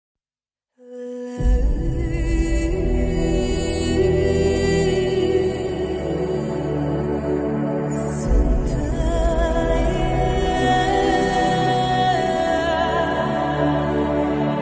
streamlabs-sub-sound.mp3